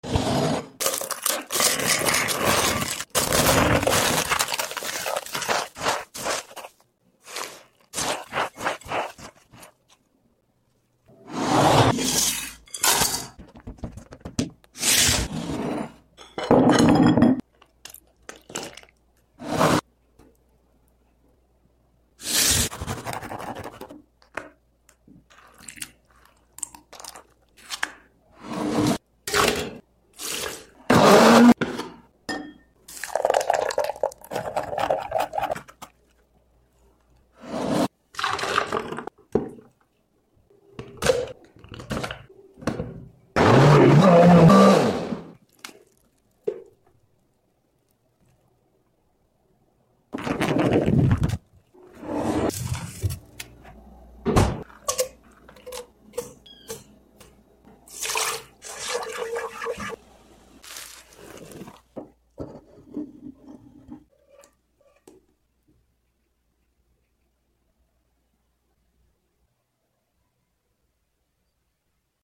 ASMR Torta de maracujá !!!